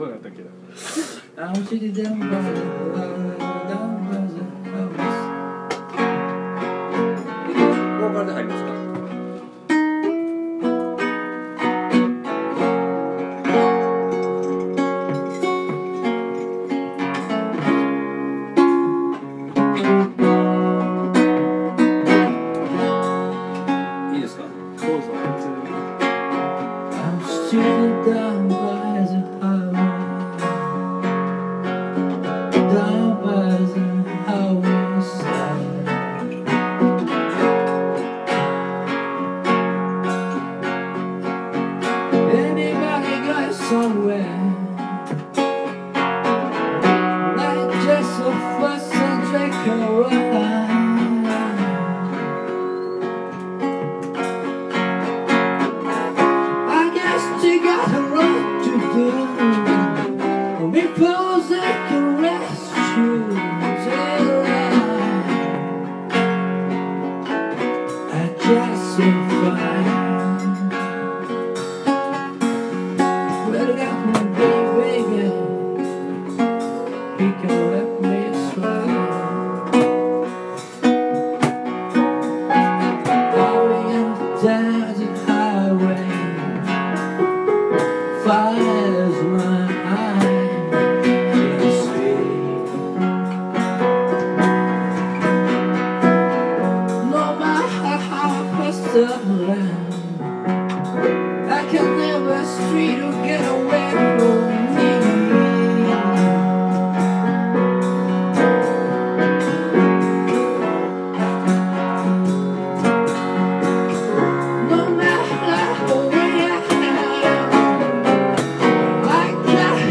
Acoustic Night Vol. 6 TURN TABLE
piano